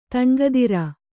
♪ taṇgadira